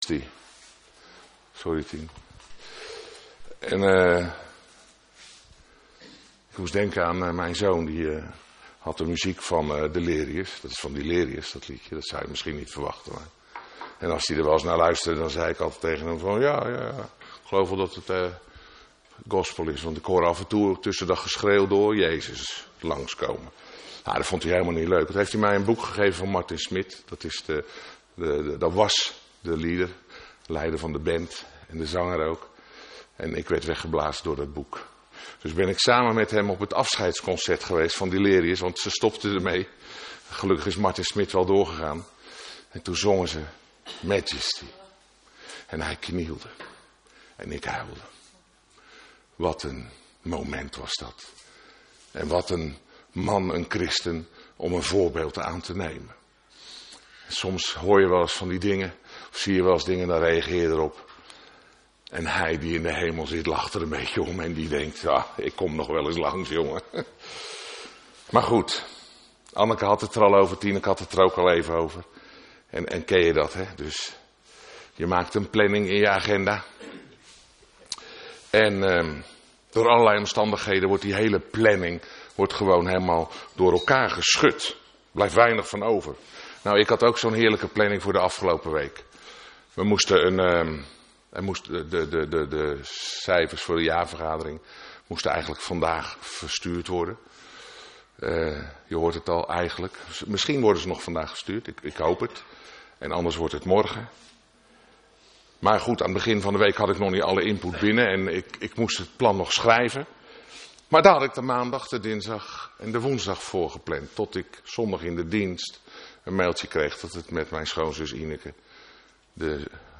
Een preek over Onrecht